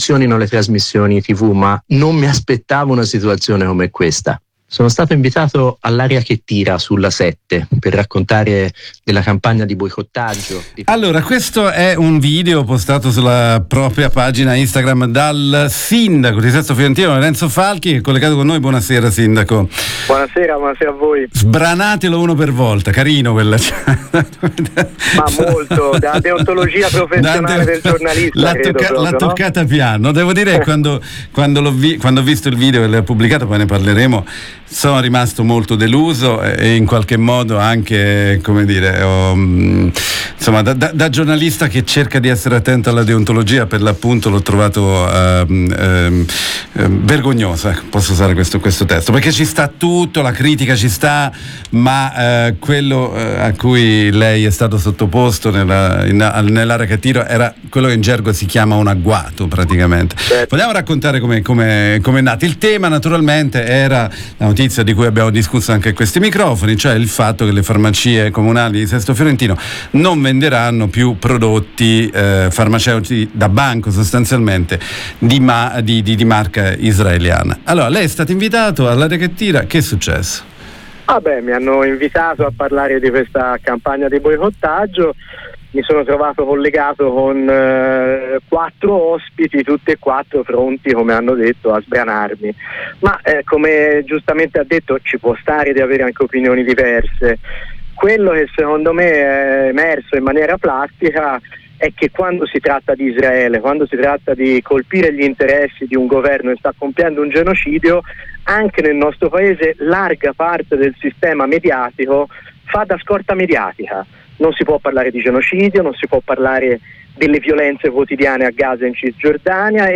Con un post su istagram il sindaco di Sesto Fiorentino, Lorenzo Falchi ha denunciato il trattamento ch gli è stato riservato nella nota trasmissione televisiva. Lo abbiamo intervistato.